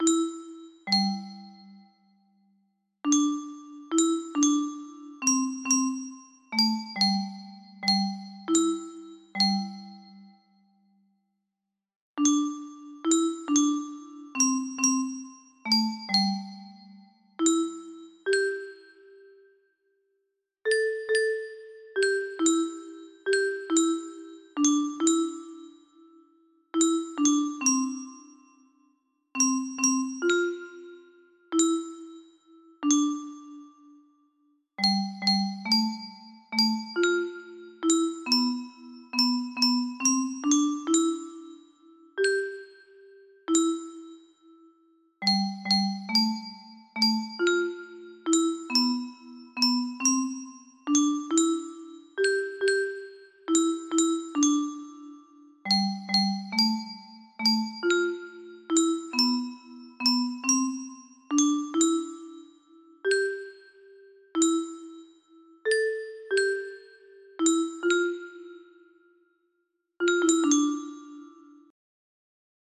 Ajustado para hacerlo un poco más lento